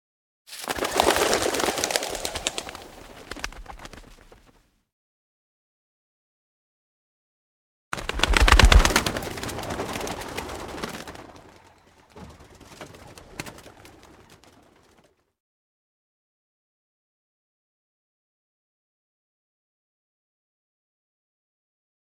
Звуки голубя
Взмах голубиных крыльев